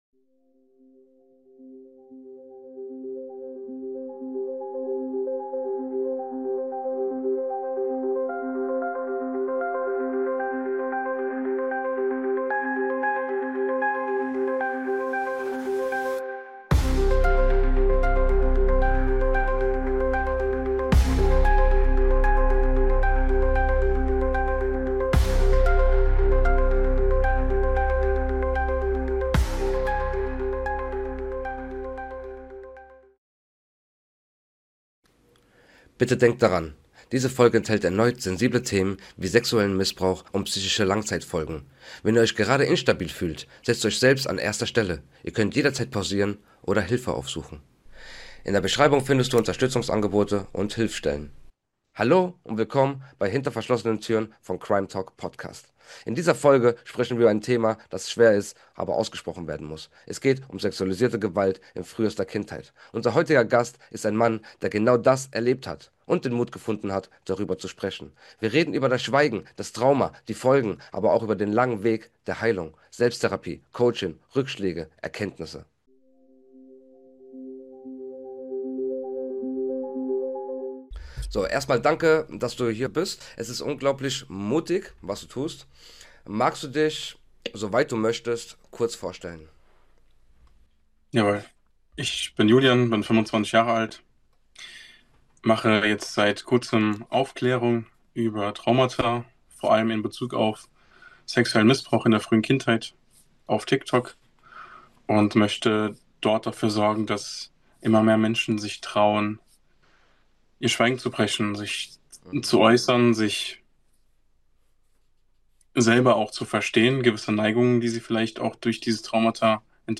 Ein Gespräch über Scham, Klarheit und Selbstermächtigung.